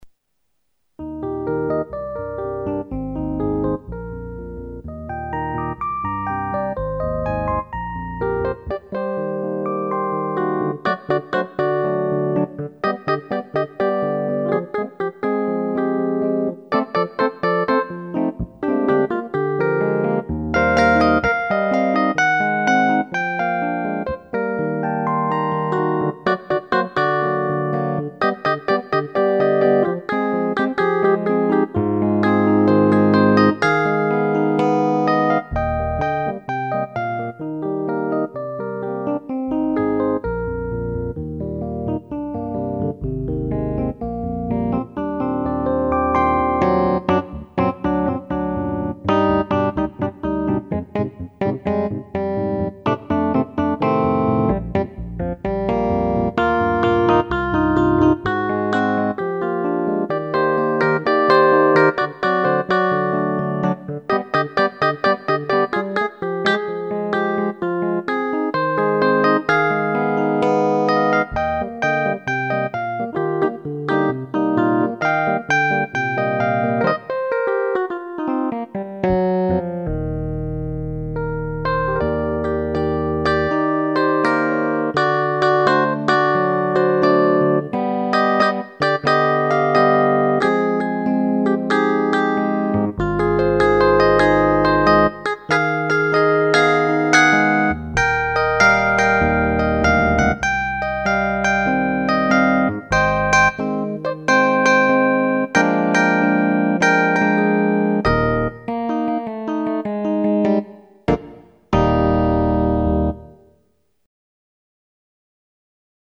e-Piano